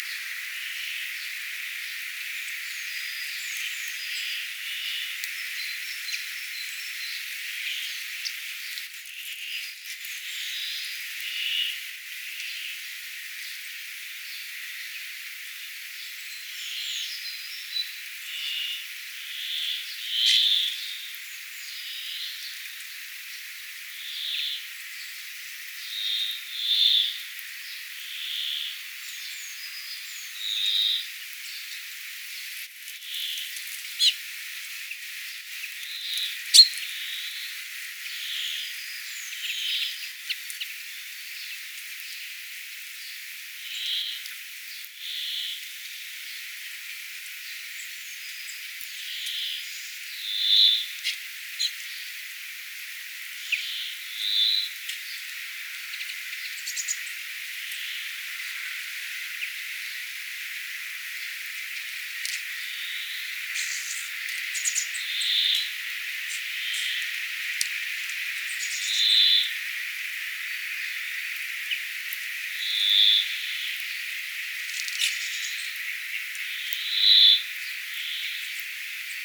kuulostaa, jos monta järripeippoa laulaa yhtä aikaa.
erilaisia_jarripeipon_laulusakeita_uusia_pidemmasti.mp3